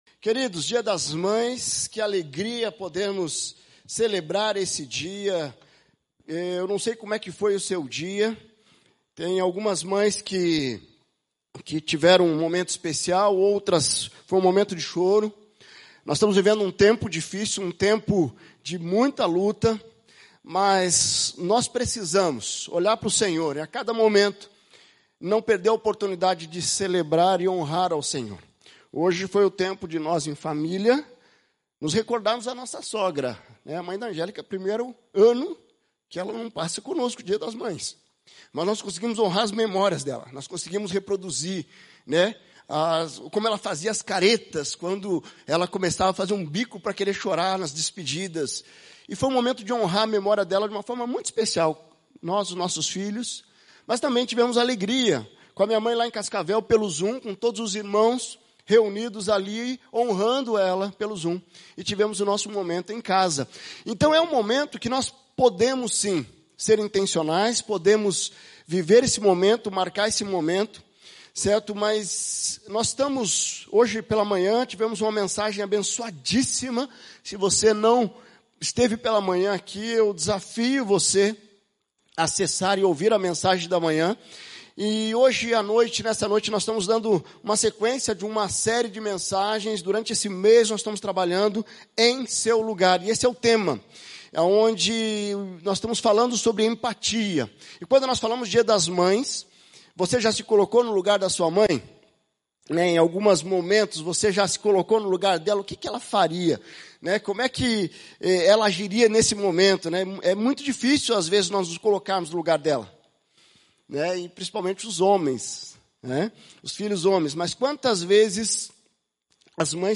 Mensagem
na Igreja Batista do Bacacheri.